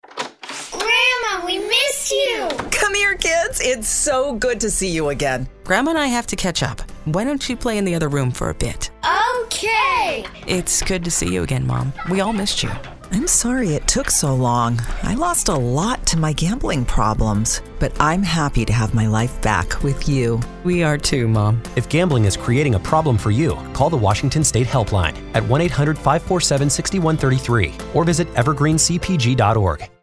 Radio Spot: